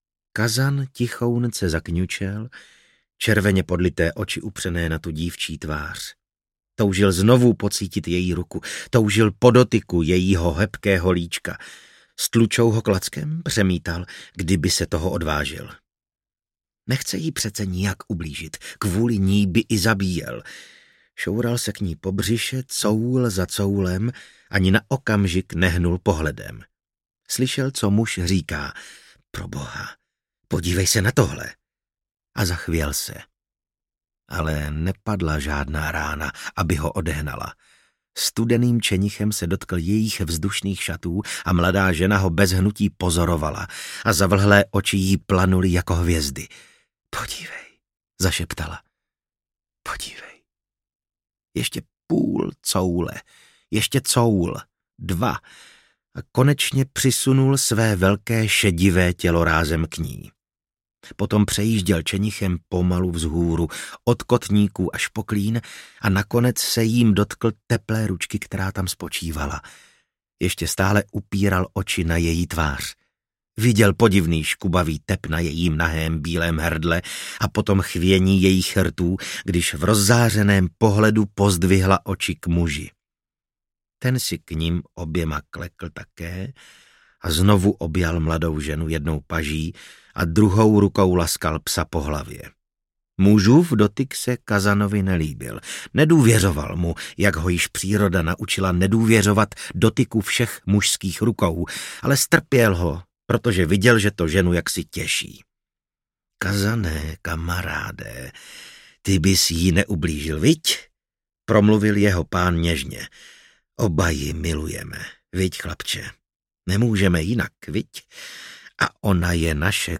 Vlčák Kazan audiokniha
Ukázka z knihy
• InterpretVasil Fridrich
vlcak-kazan-audiokniha